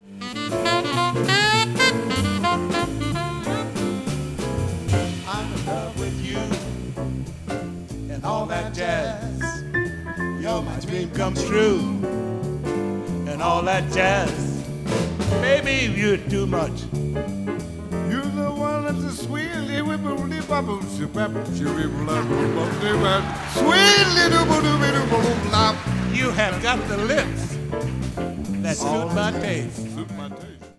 croon